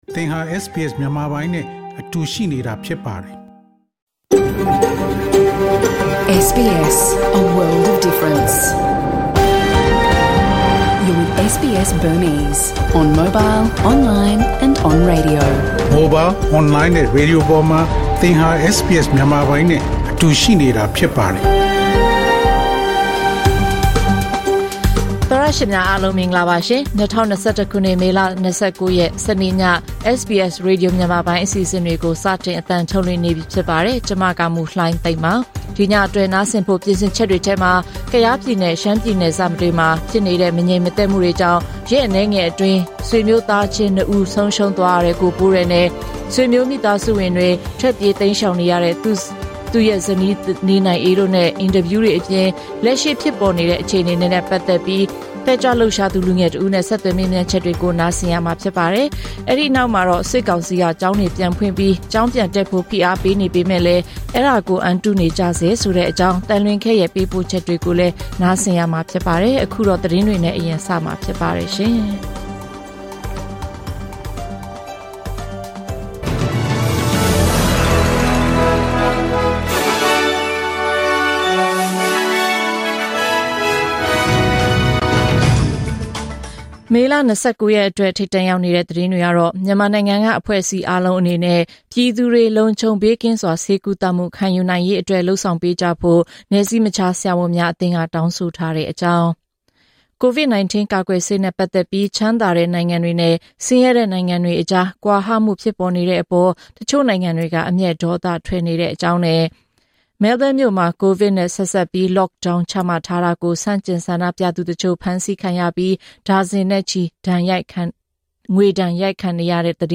SBS မြန်မာပိုင်း အစီအစဉ်ပေါ့ကတ်စ် သတင်းများ။ Source: SBS Burmese